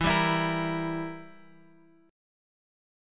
Edim chord {x 7 5 x 5 6} chord
E-Diminished-E-x,7,5,x,5,6.m4a